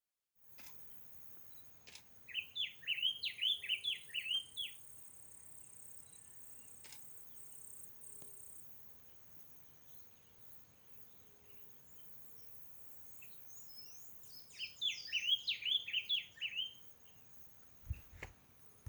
Diademed Tanager (Stephanophorus diadematus)
Province / Department: Entre Ríos
Location or protected area: San Salvador
Condition: Wild
Certainty: Photographed, Recorded vocal